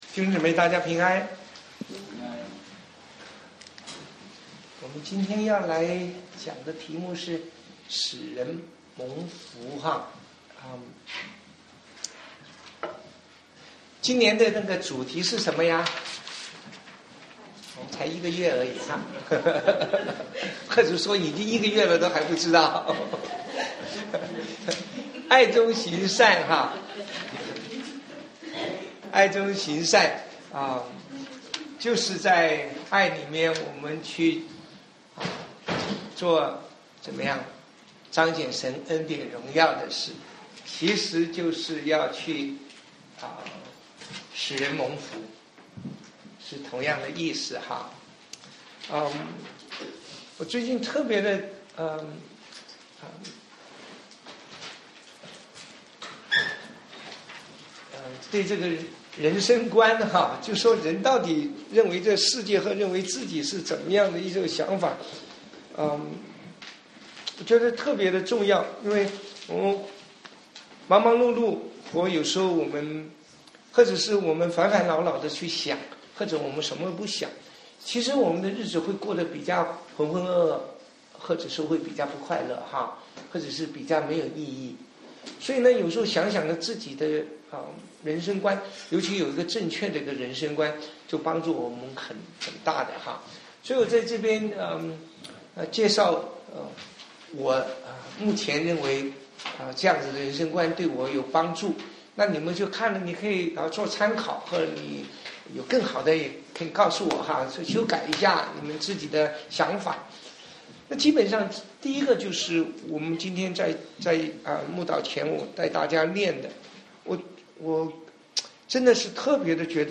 Play Audio 聽講道錄音 使人蒙福 中心思想 : 讓我們成為使人蒙福的人 馬太福音 5:1-12 引言 : 一個蒙福與祝福的 人生觀 1.